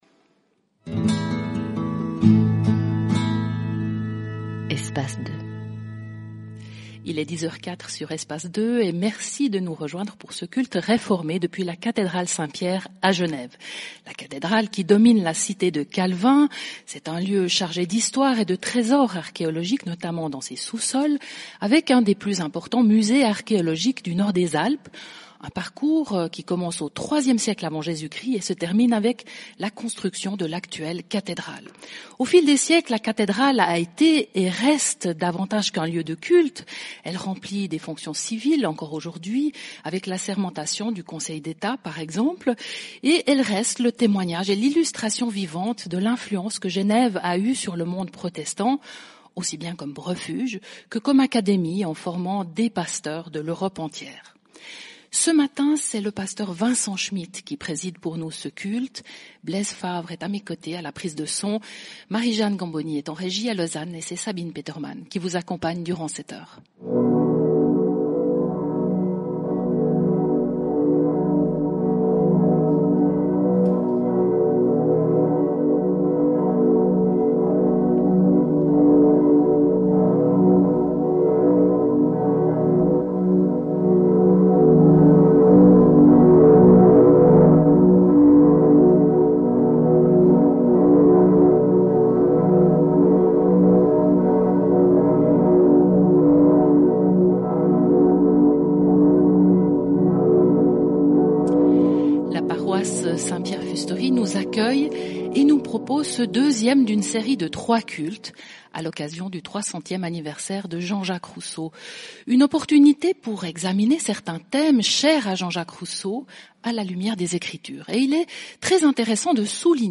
Prédication
Écouter le culte Votre navigateur ne prend pas en charge la lecture de ce format audio.